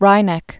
(rīnĕk)